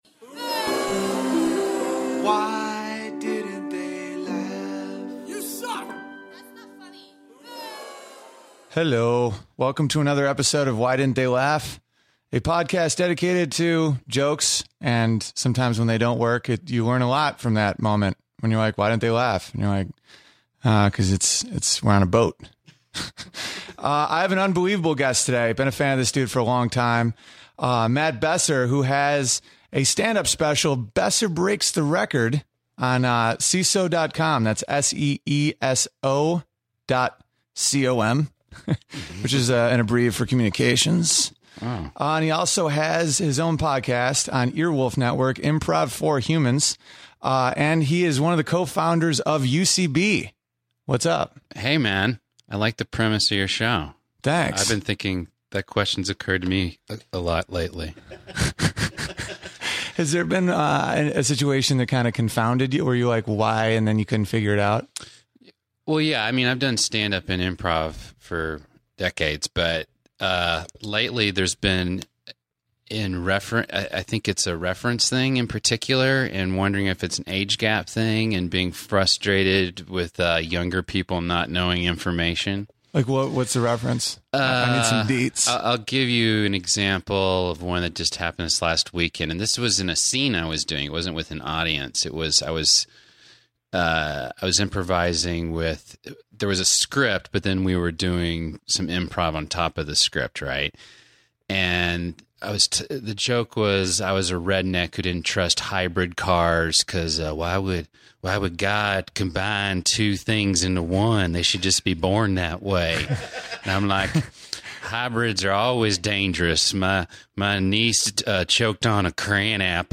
Legendary comedian Matt Besser talked to me about starting UCB, the advantages of being in a dinosaur costume, and what made him throw the chips. Been a big fan of this dude for a while and it's great to be able to ask one of the founding members of Upright Citizens Brigade about what makes great improv.